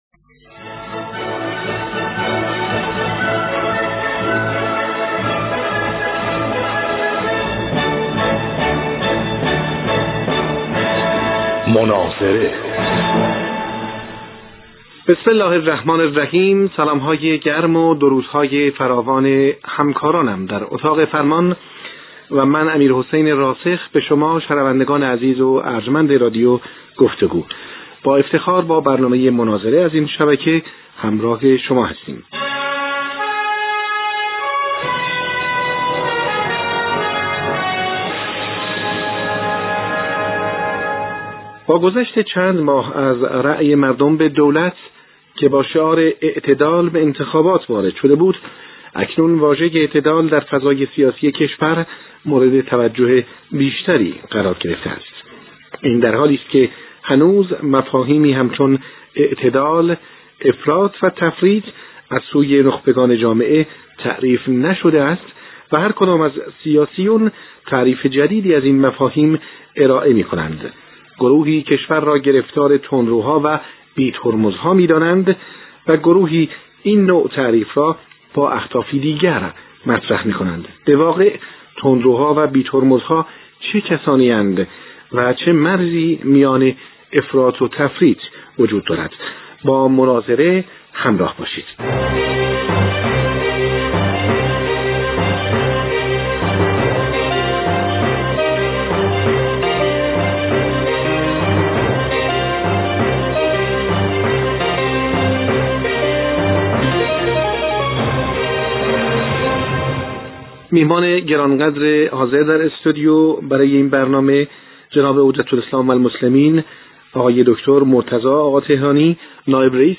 اکثریت درمسائل سیاسی ملاک اعتدال است + صوت کامل مناظره